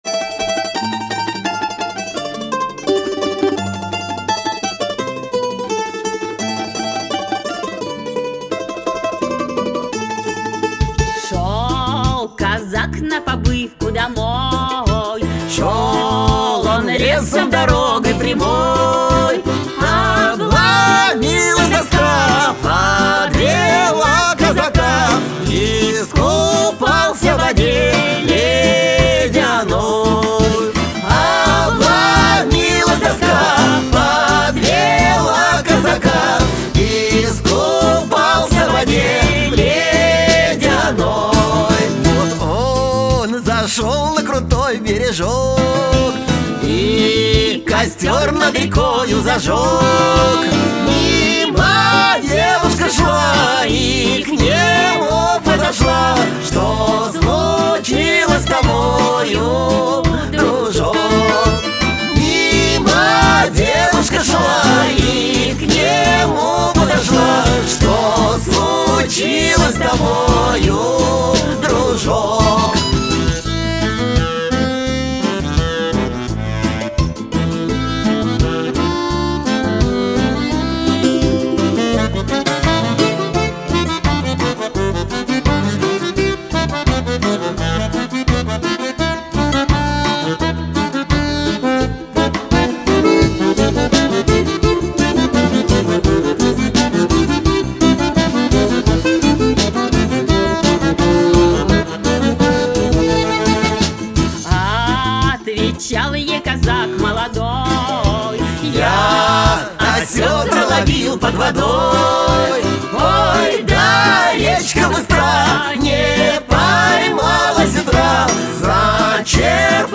♦   Dieses neue musikprojekt ist eine fusion aus russuscher trad. musik und dem westlichen rock'n'roll, wo man die lead guitar durch ein wildes akkordeon ersetzt hat.
♦   emotional, raw, savagely passionate and virtuoso all at the same time: it's russian rock'n'roll, and we like it!.